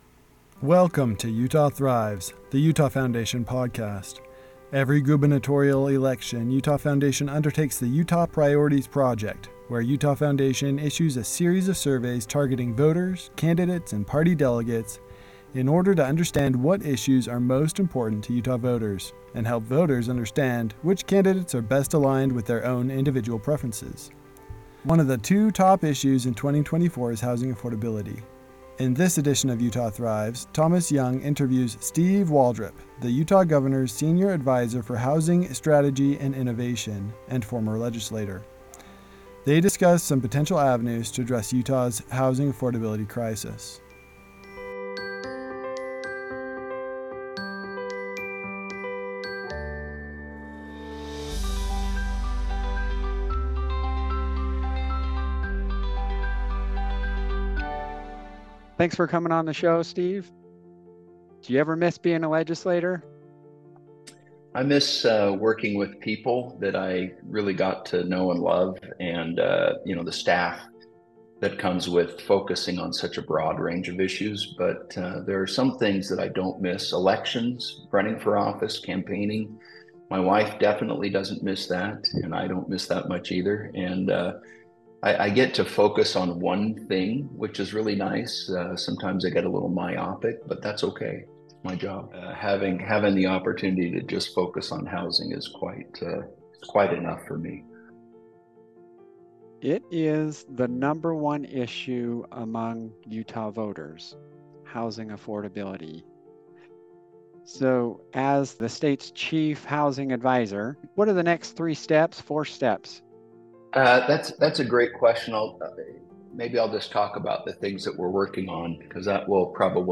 Utah Thrives Podcast | Interview with Steve Waldrip